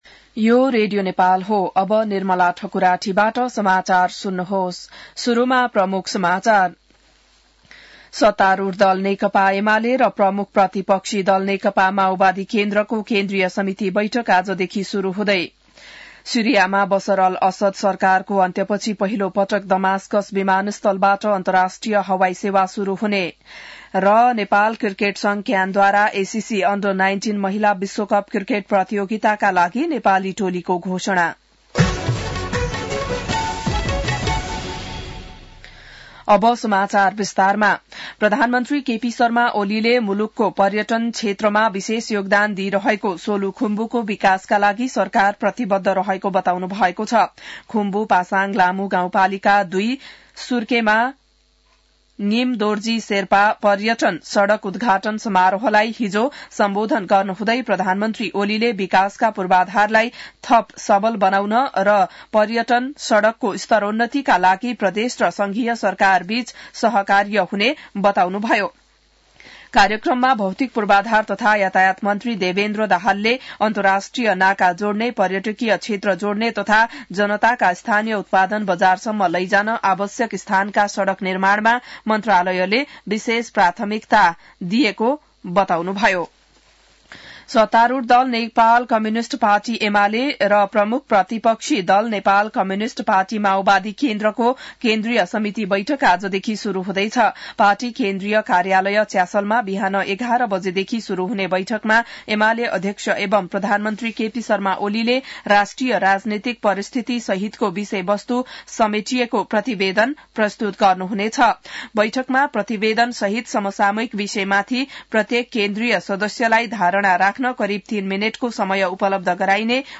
An online outlet of Nepal's national radio broadcaster
बिहान ९ बजेको नेपाली समाचार : २२ पुष , २०८१